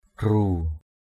/ɡ͡ɣru:/ (d.) thầy = maître. teacher, professor. gru khik g~% A{K giám thị = surveillant. gru bac g~% bC giáo viên = instituteur. gru pataow g~% p_t<| giáo sư =...